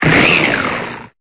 Cri d'Opermine dans Pokémon Épée et Bouclier.